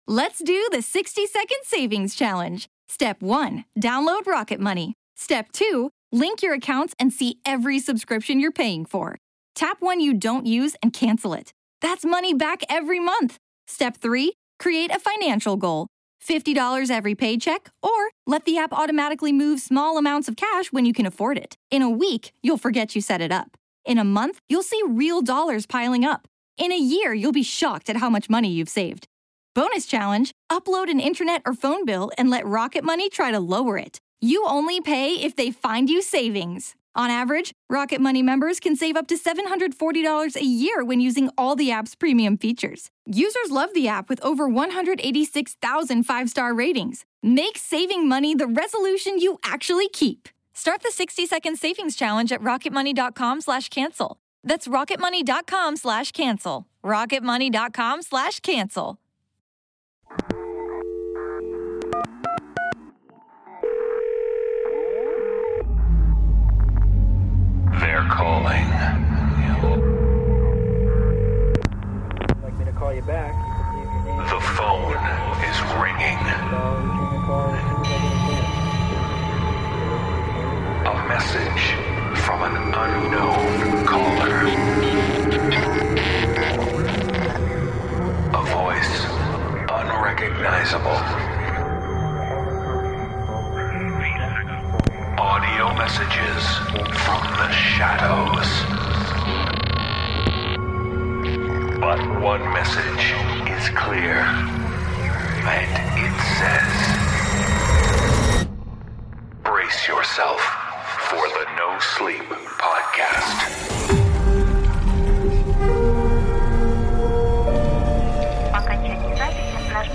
The voices are calling with tales of vexing verisimilitude.